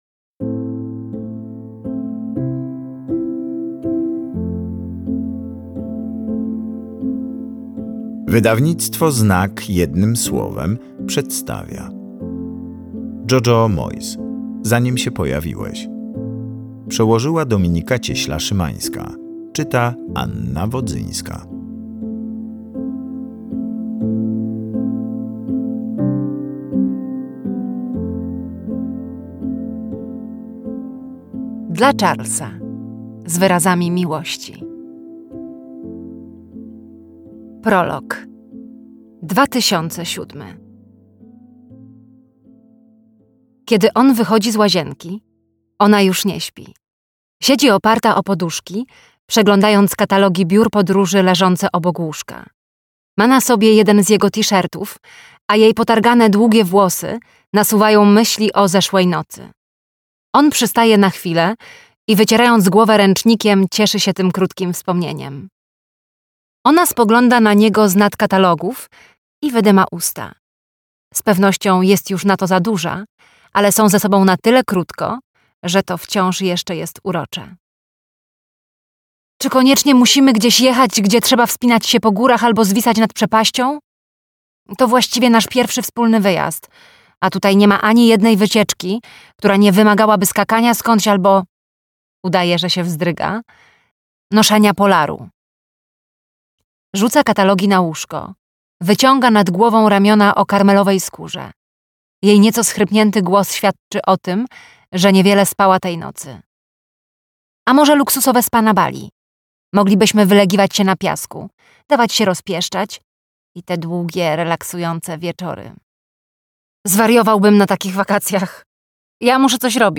Zanim się pojawiłeś - Jojo Moyes - audiobook